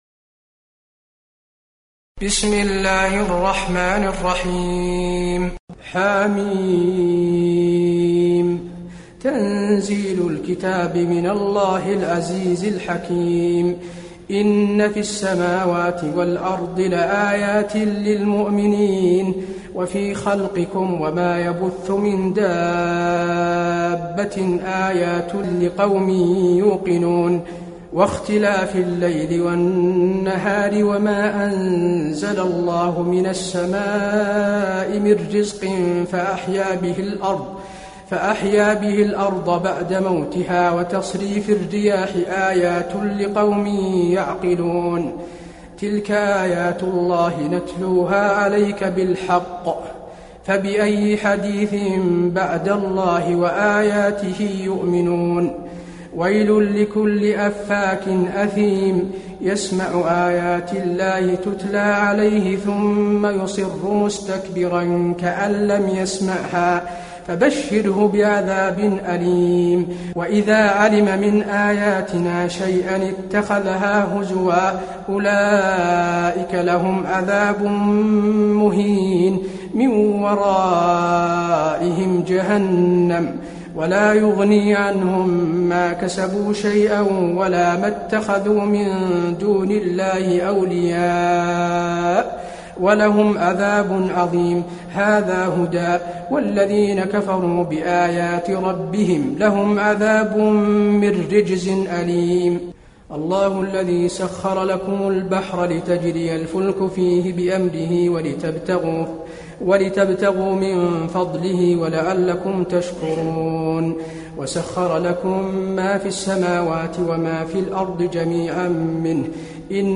المكان: المسجد النبوي الجاثية